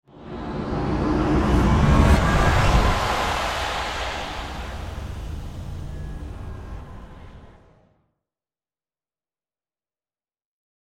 جلوه های صوتی
دانلود صدای ماشین 15 از ساعد نیوز با لینک مستقیم و کیفیت بالا